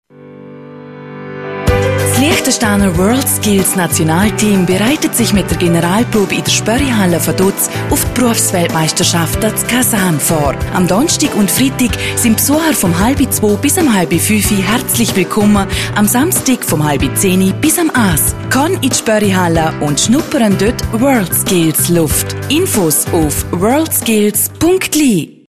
Mai 2019, 09.30 bis 13.00 Uhr Radiospot